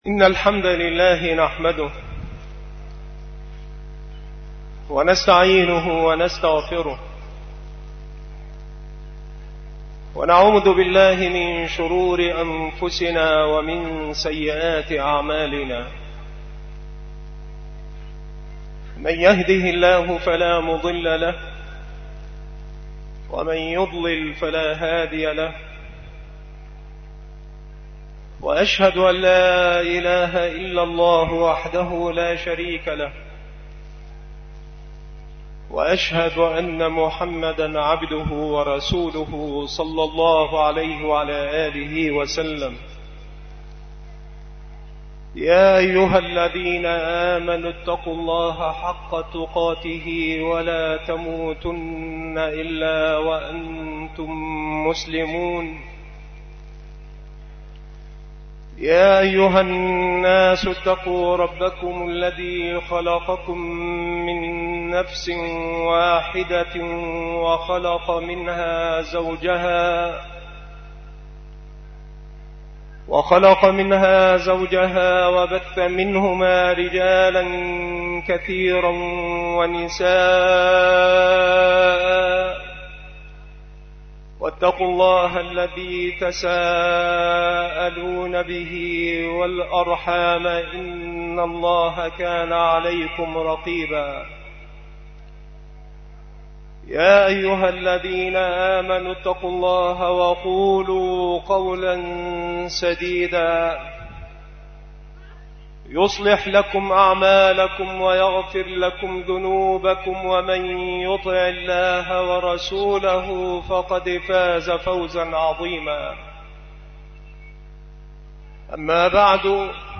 خطبة عيد الأضحى لعام 1428هـ ..
خطب العيدين
مكان إلقاء هذه المحاضرة بمركز شباب سبك الأحد - أشمون - محافظة المنوفية - مصر